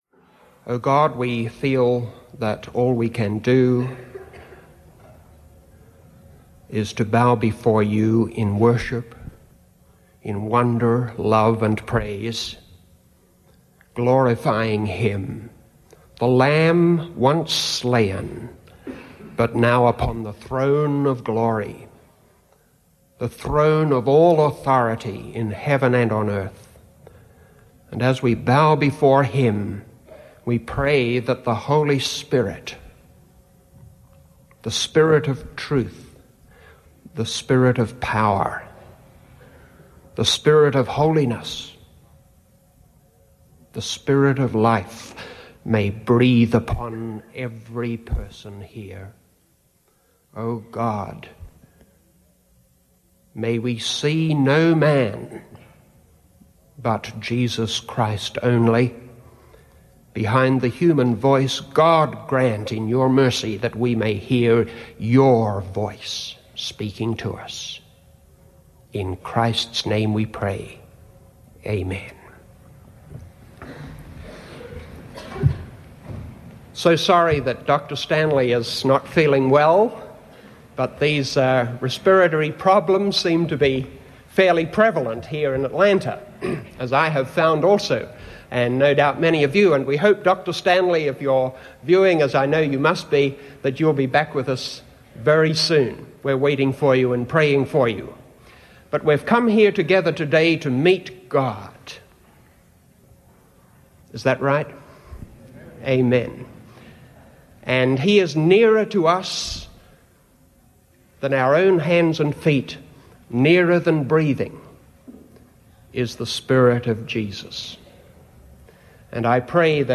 In this sermon, the preacher discusses the good news, or the gospel, found in the Bible. He emphasizes that God has done what we could not do, providing a way for us to have a relationship with Him. The preacher presents four weights that can be put on our side of the balance to ensure our salvation.